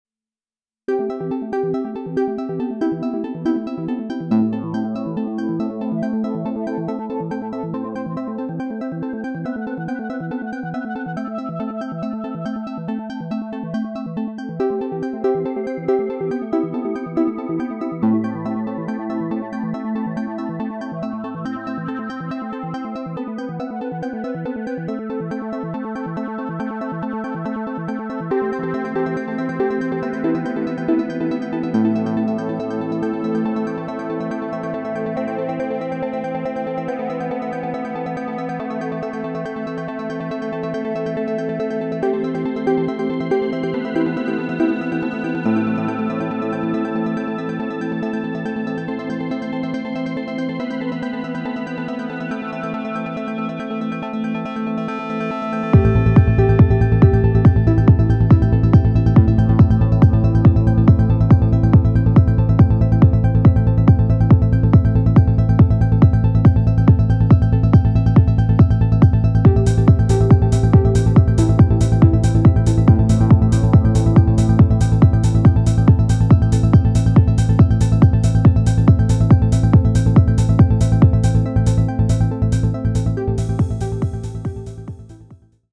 All sounds apart from drums are Cycles tracks. One track uses Cycles’ delay and reverb. Other melodic tracks are sent through Digitakt’s delay and reverb. Bass track is dry. Digitakt compressor on top.
1. Cycles only, no bass;
2. Cycles with Digitakt FX, no bass;
3. Cycles with bass, Digitakt FX, kick, hat and sidechain
I’ve used a ton of delay and reverb in Digitakt to spread and smear Cycles’ mono sounds into underlying pad/texture.